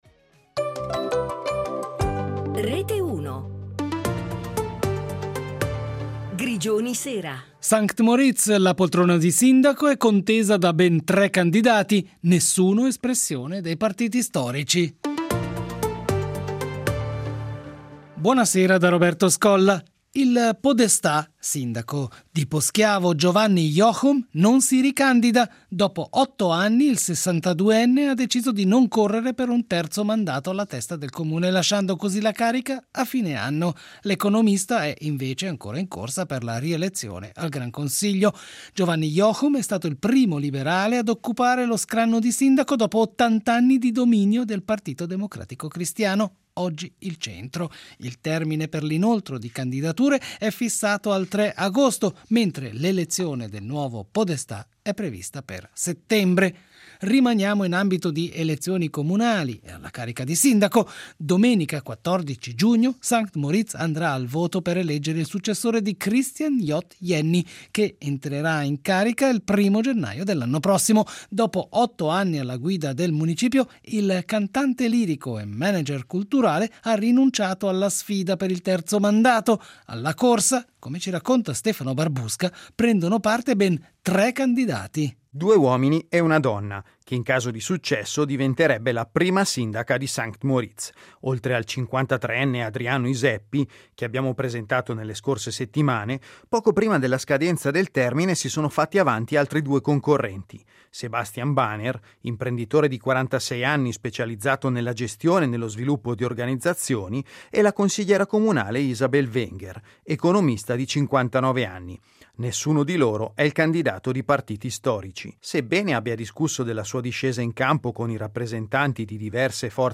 In questa edizione anche la contesa per il sindacato a St. Moritz e l’intervista al presidente Maurizio Michael sulle ambizioni del PLR alle prossime elezioni cantonali